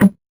CS_VocoBitA_Hit-11.wav